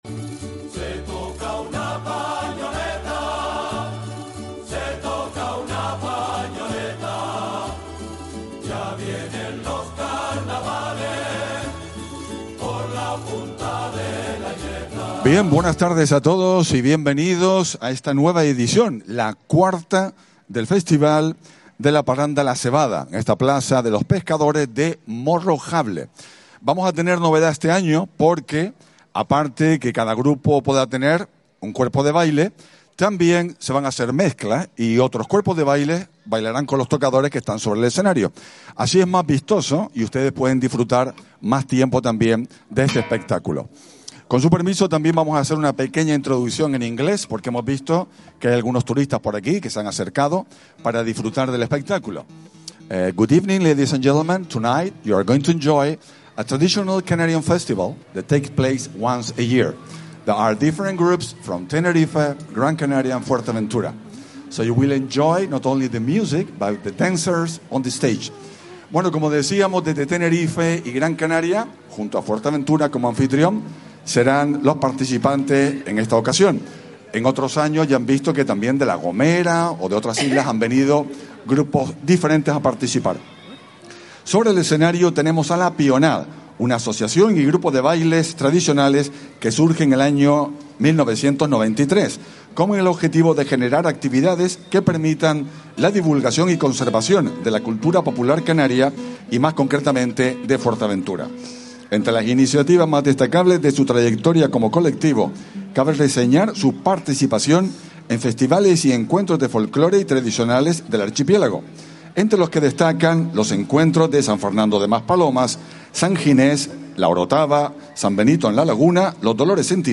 Comenzamos a emitir el IV Festival La Sebada, traemos al grupo La Pioná.